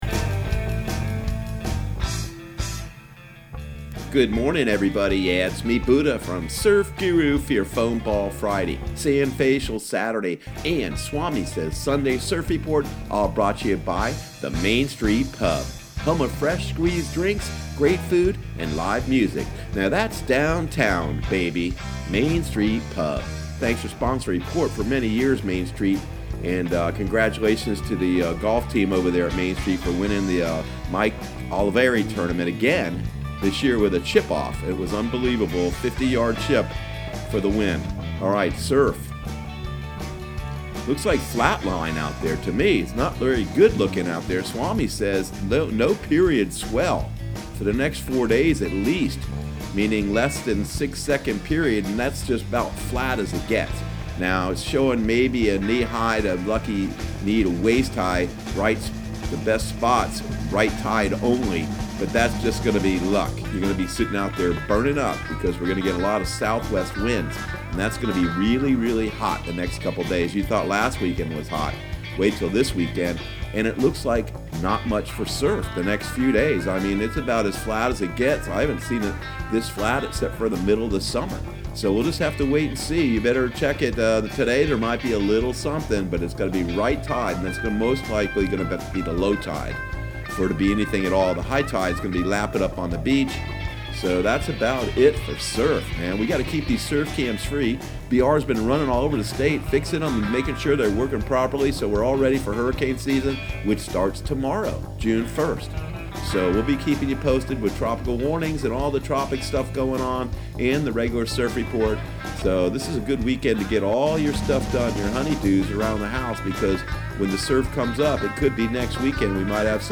Surf Guru Surf Report and Forecast 05/31/2019 Audio surf report and surf forecast on May 31 for Central Florida and the Southeast.